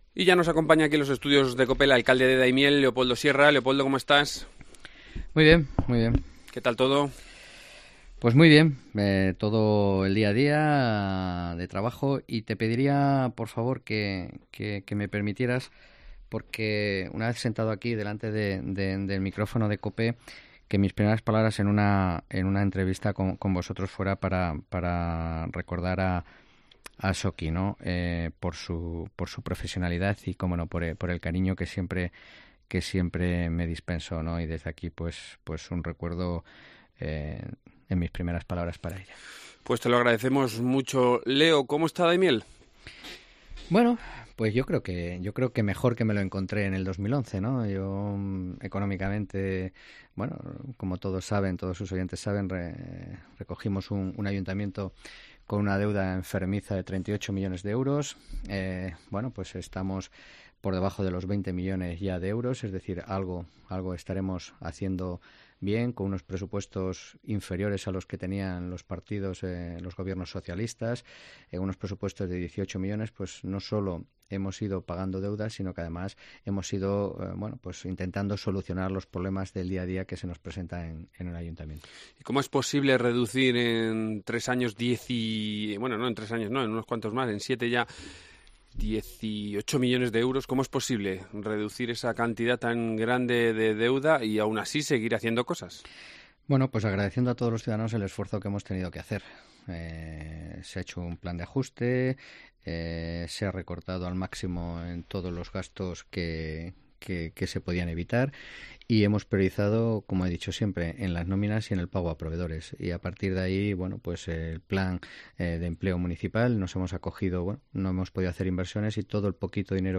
Leopoldo Sierra, alcalde de Daimiel
El alcalde de Daimiel Leopoldo Sierra ha asegurado hoy en Cope que no "puede ser que los ayuntamientos paguen la deuda de la Junta", y ha reclamado un calendario de pagos a los consistorios.